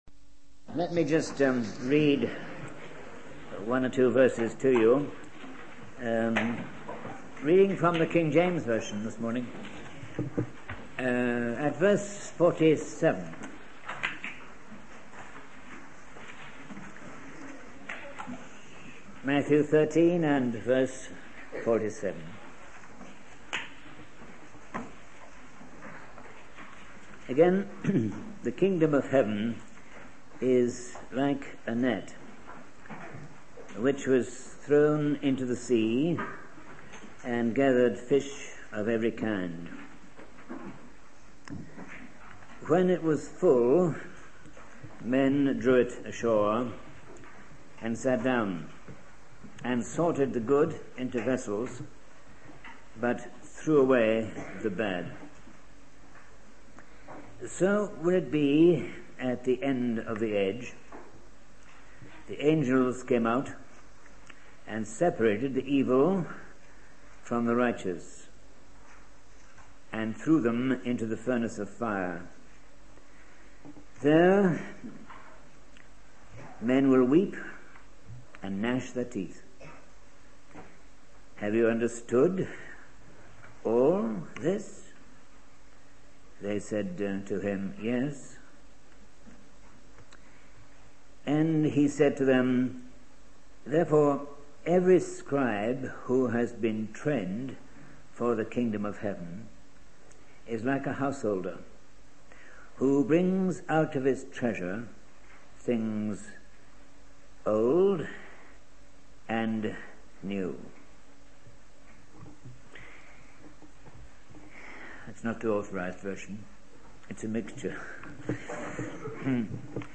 In this sermon, the preacher emphasizes the importance of the parable of the net in the sea. He highlights the lessons to be learned from this parable, such as the authority for mass evangelism, the sanction for mission work, and the necessity for church organization. The preacher also discusses the fact of severance, explaining how the good fish are gathered into vessels while the bad fish are thrown away.